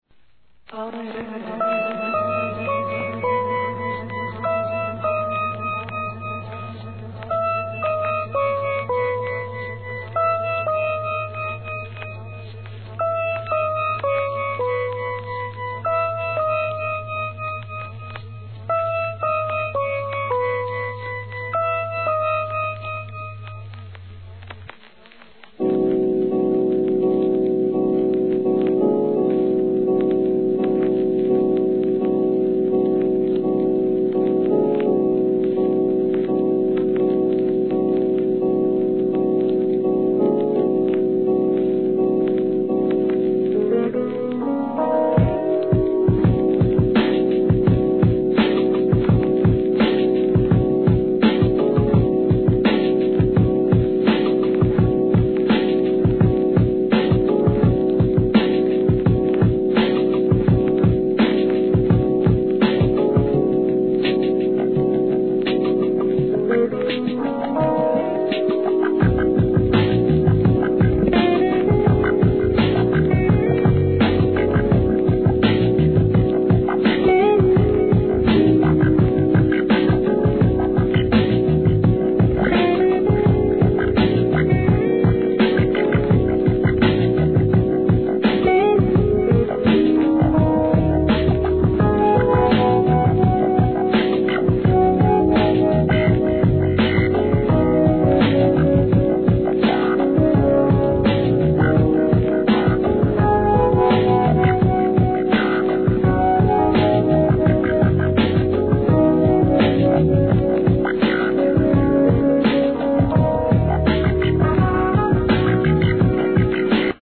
HIP HOP/R&B
チャカポコ鳴るBEATにシンセ、ギター、フルートが奏でるアフリカンFUNK!!